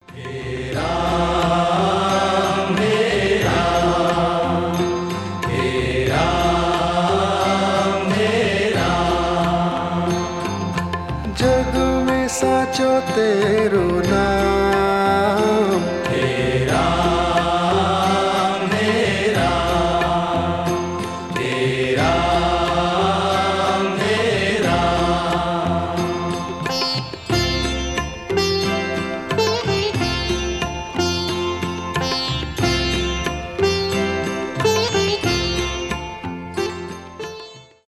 спокойные , индийские